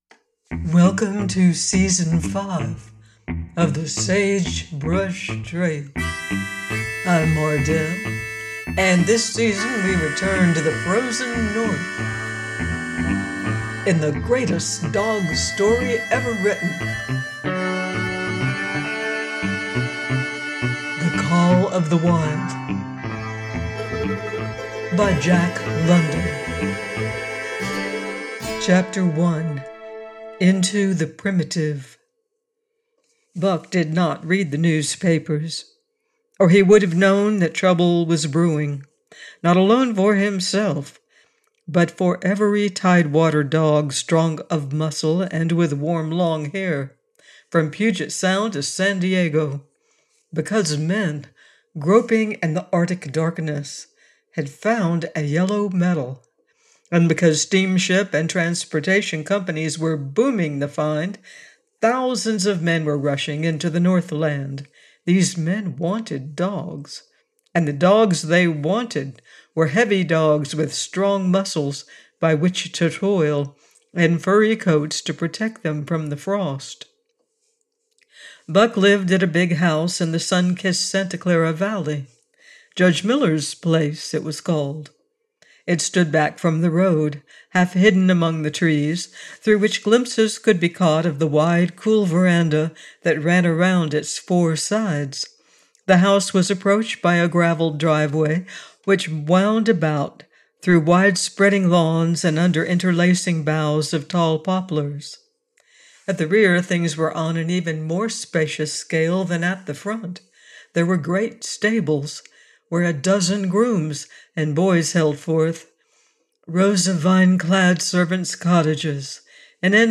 The Call Of The Wild: by Jack London - AUDIOBOOK